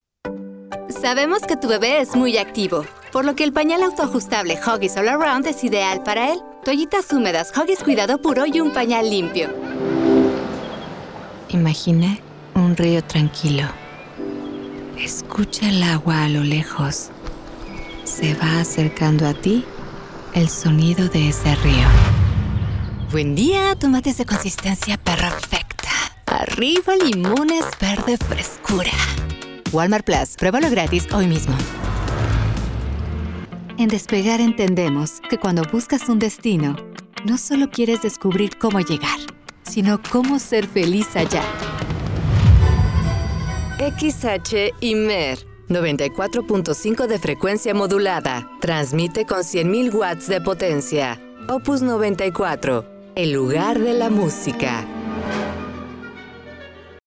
Commerciale, Naturelle, Cool, Polyvalente, Corporative
Commercial